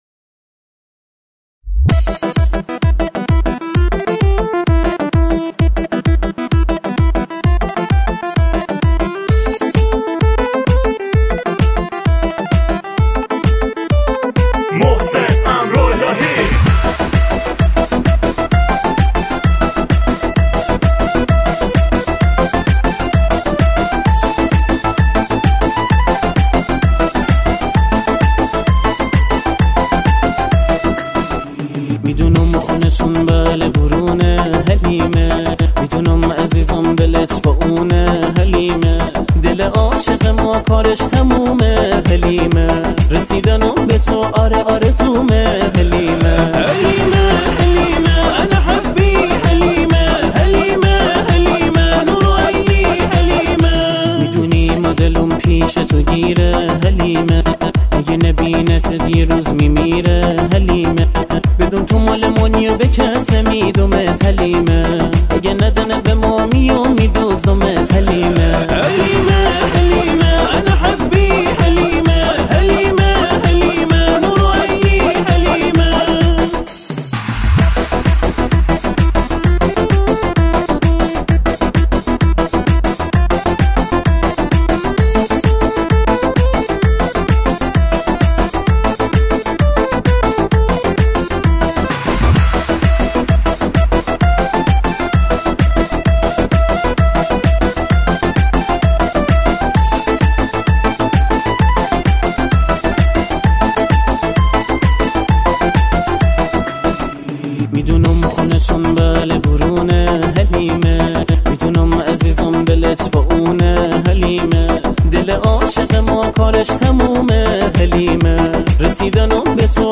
بندری شاد